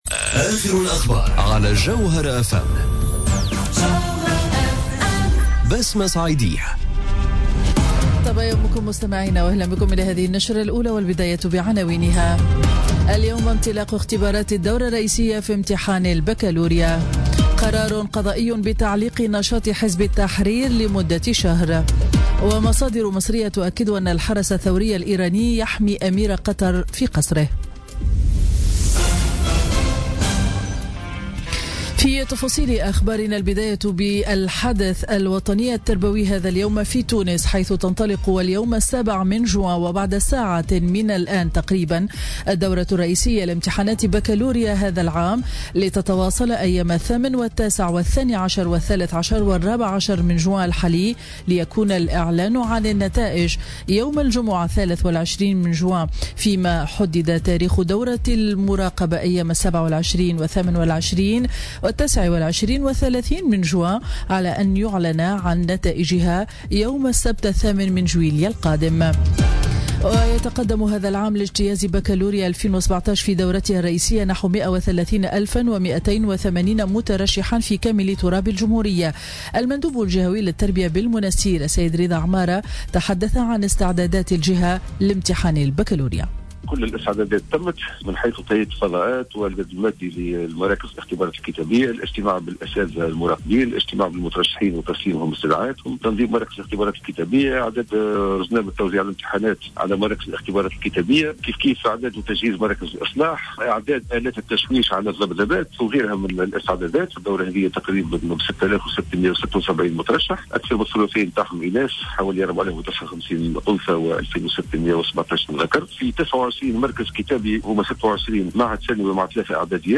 نشرة أخبار السابعة صباحا ليوم الإربعاء 7 جوان 2017